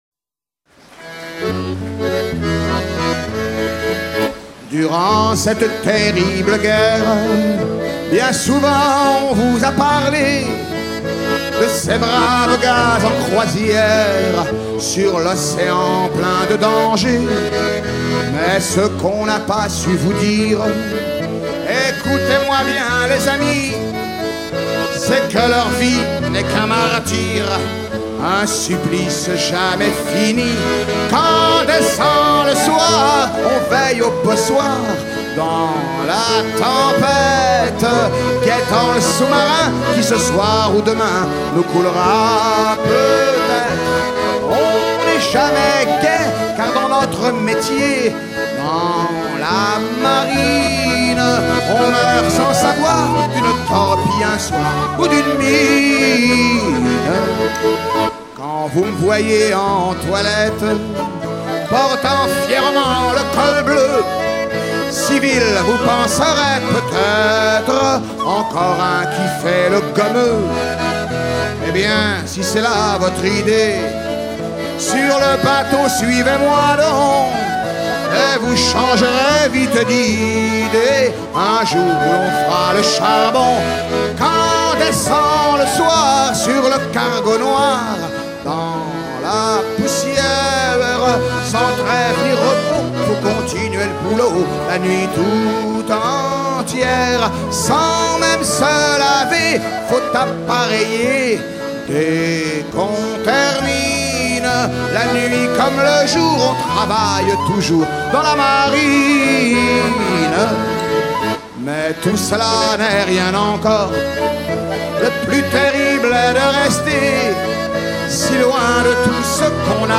Chants de marins en fête - Paimpol 1997
Pièce musicale éditée